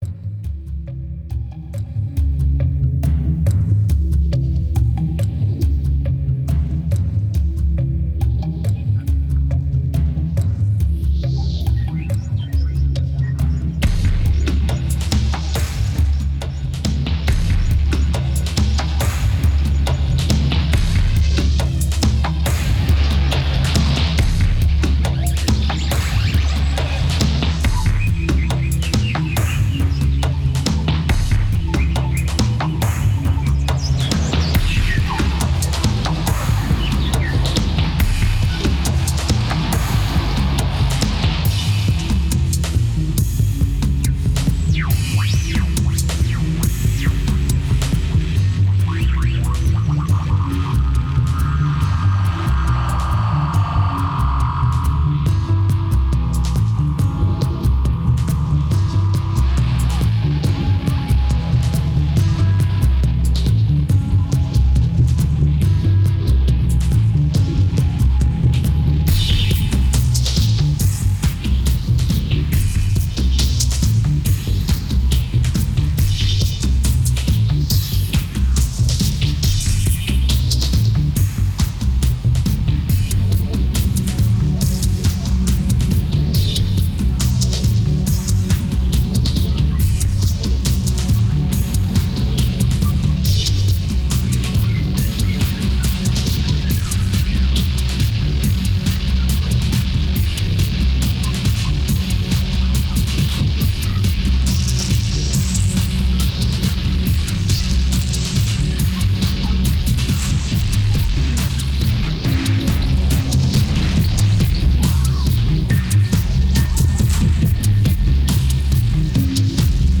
2217📈 - -45%🤔 - 139BPM🔊 - 2009-04-23📅 - -379🌟